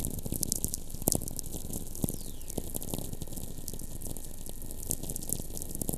Heidelberg, Germany